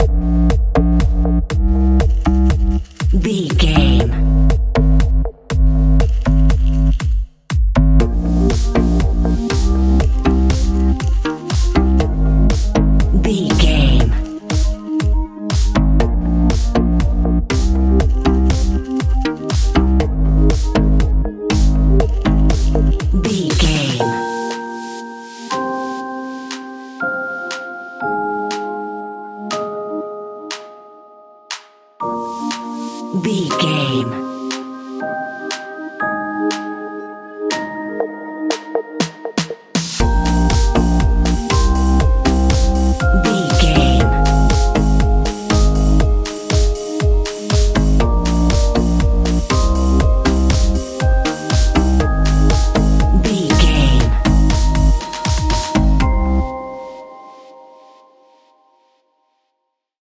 Uplifting
Aeolian/Minor
bouncy
synthesiser
drum machine
percussion
sleigh bells